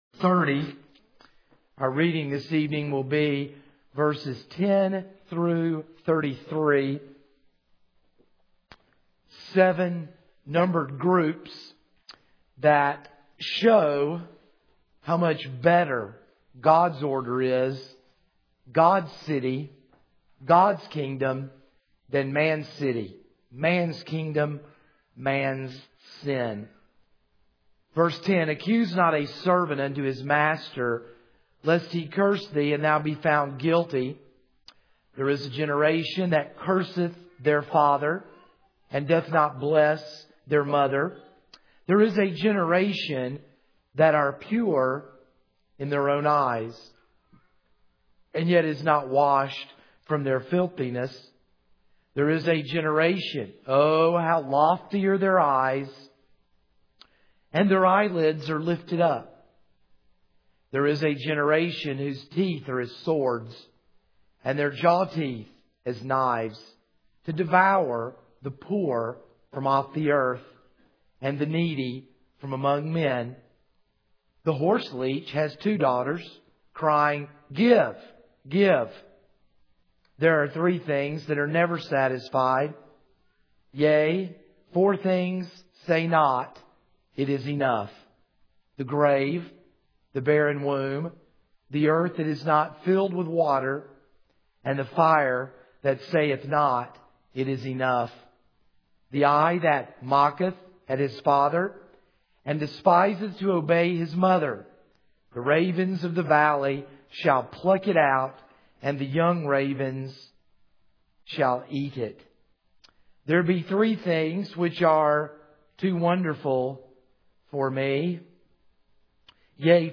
This is a sermon on Proverbs 30:10-33.